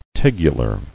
[teg·u·lar]